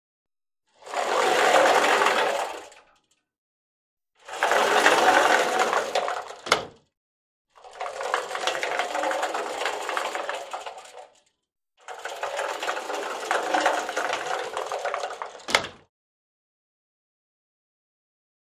Closet Sliding Door | Sneak On The Lot
Sliding Closet Door; Hotel Slide Closet Door Open / Close ( Twice ). Medium Close Perspective